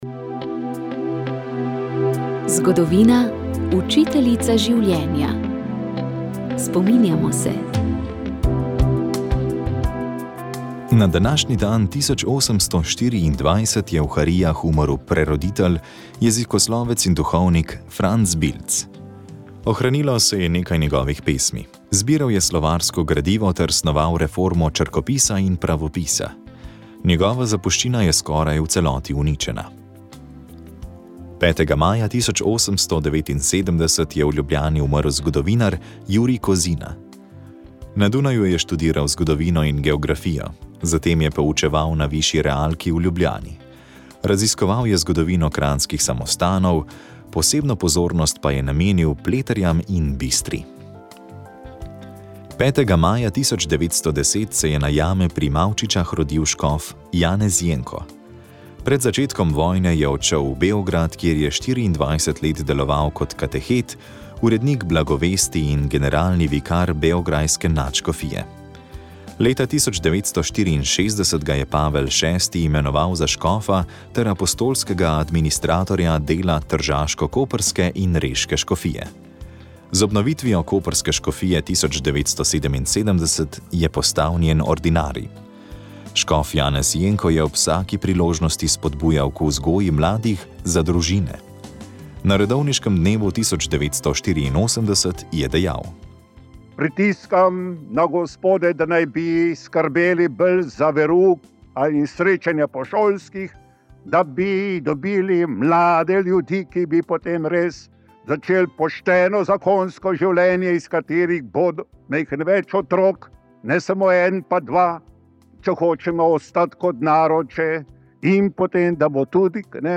Pričevanje 2. del